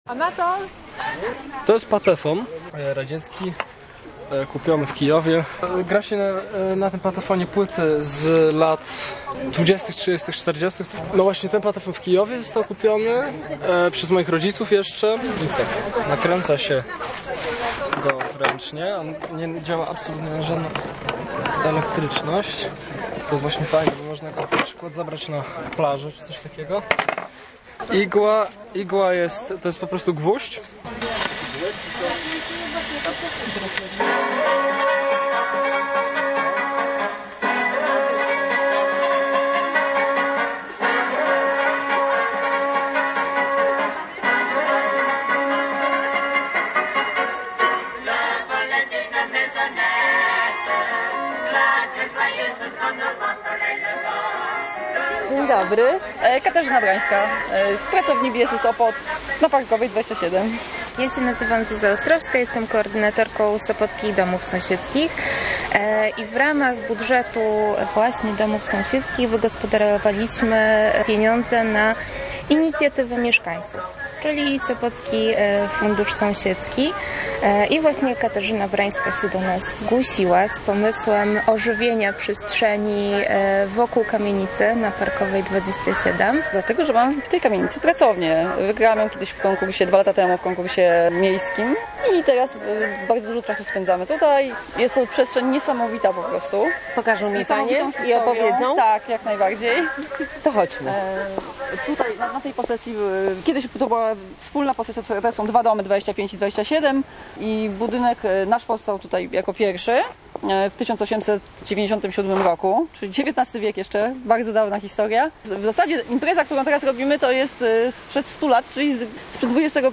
Sąsiedzi i przyjaciele postanowili wspólnie spędzić czas, wrócić do wspomnień i zacieśnić więzy. Grał patefon, warzywa i mięso smażyły się w oryginalnej wędzarnio-grillownicy zwanej „lokomotywą”, ogród odżył.